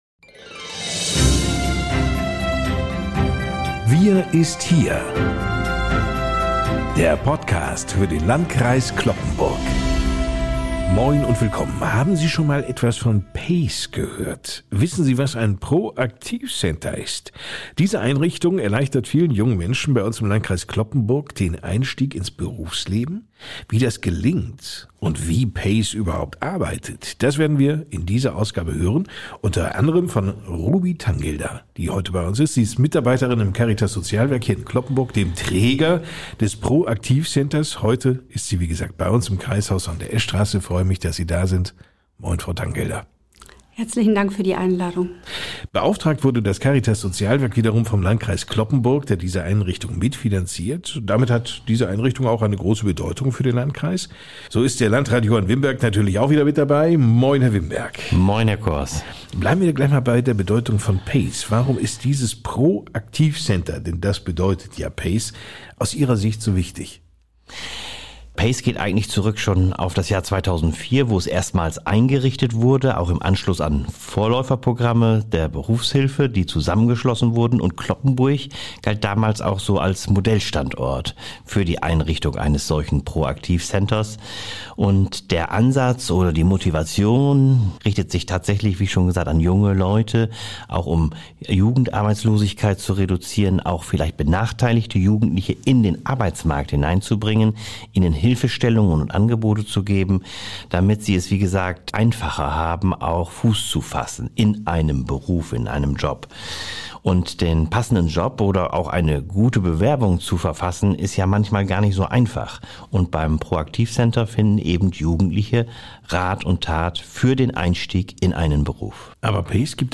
Gemeinsam beleuchten sie facettenreich das Thema und gehen dabei im Podcast in die Tiefe.